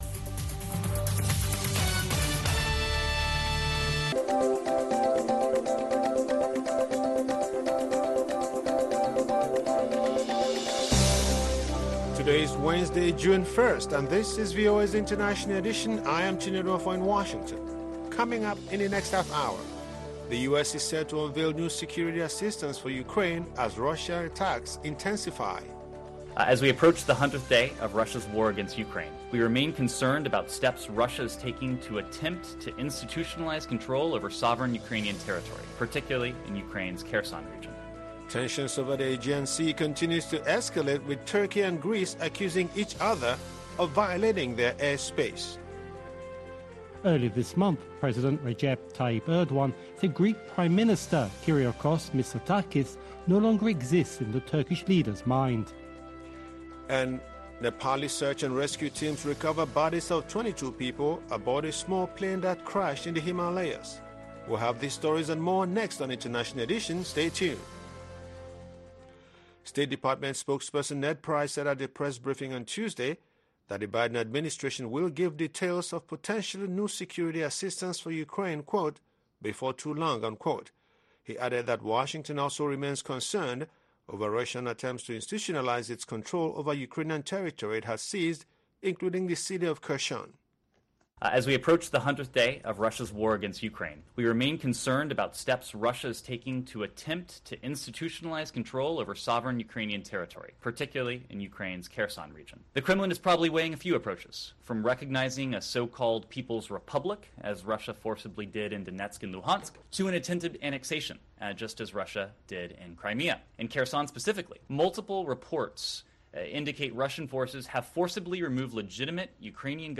International Edition delivers insight into world news through eye-witnesses, correspondent reports and analysis from experts and news makers. We also keep you in touch with social media, science and entertainment trends